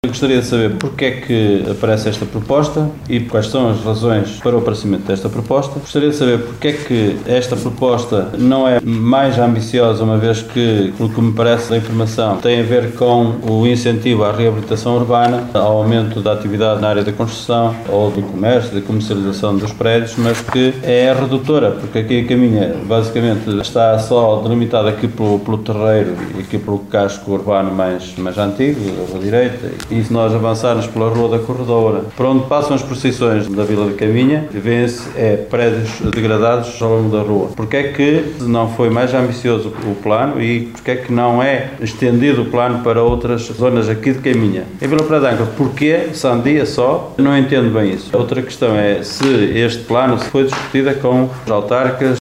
Durante a última reunião da Câmara de Caminha, o vereador do PSD, Flamiano Martins, quis saber a razão das escolha destas áreas e se os respectivos presidentes de Junta foram ouvidos pelo executivo camarário: